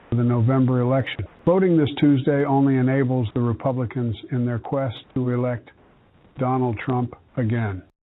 Deep fakes
biden_ai_robocall_primary_2024.mp3